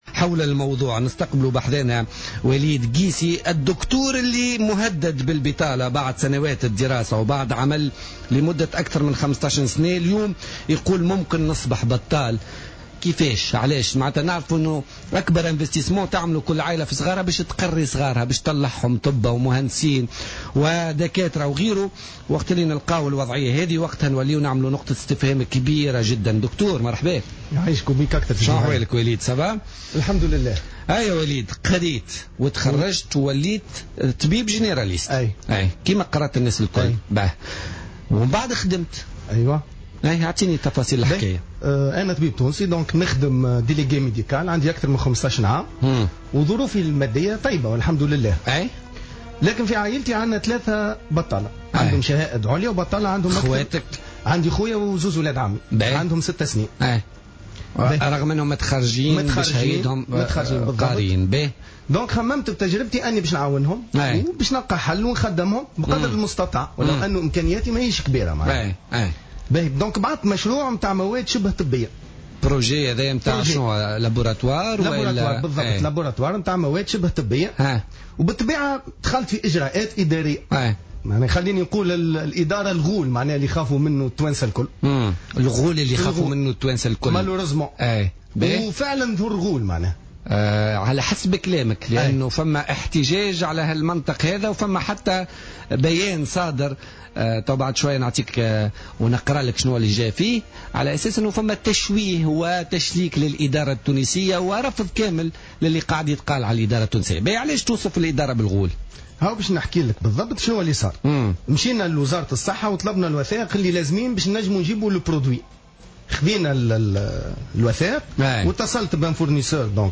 وأضاف خلال مداخلة له في برنامج "بوليتيكا" اليوم الجمعة أن المواد الطبية التي قام باستيرادها والرابضة بالميناء التجاري أصبحت مهدّدة بالتلف، خاصة وأنه أمضى 3 أشهر كاملة دون أن يتوصّل إلى نتيجة.